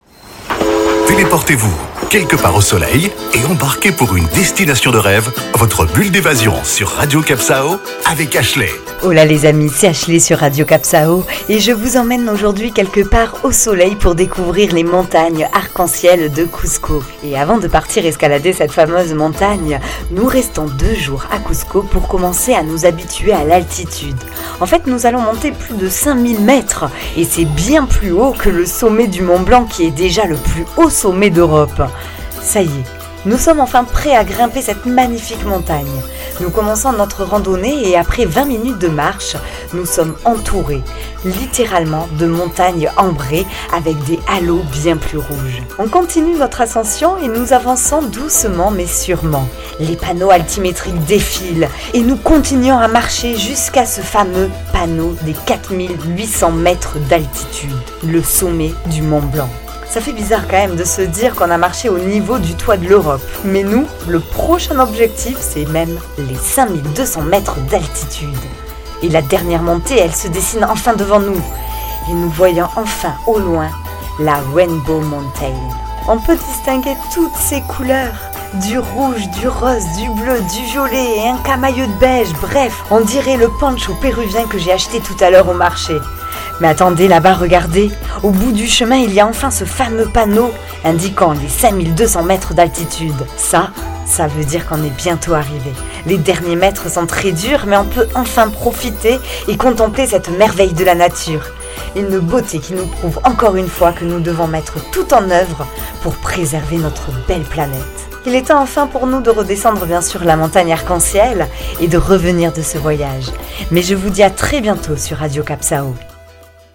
Carte postale sonore : sortez vos chaussures de randonnée pour vous envoler "over the rainbow", ce lieu magique vous en fera voir de toutes les couleurs !